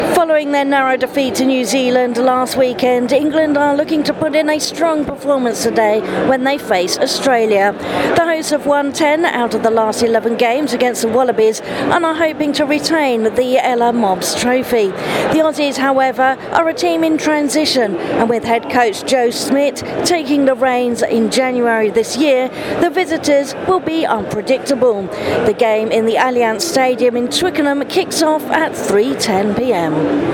reports from the Allianz Stadium in Twickenham: